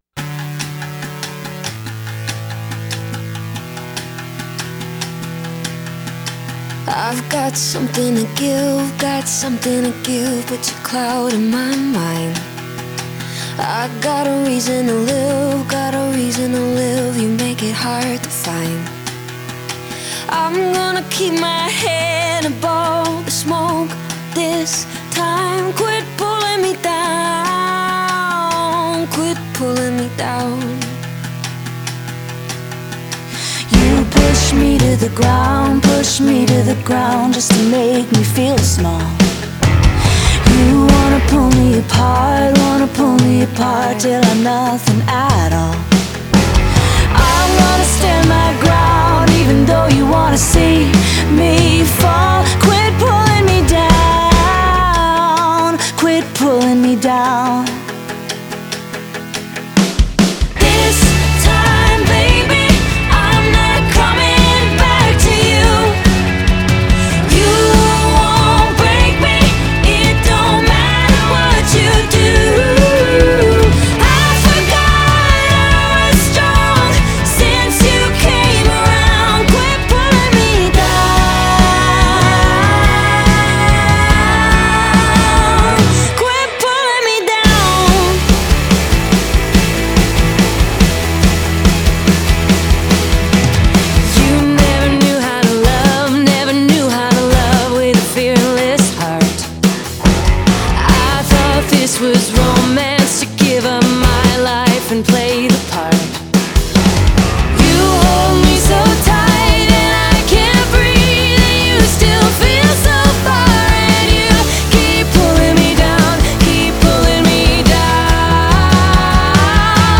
Genre: Indie Pop, Alternative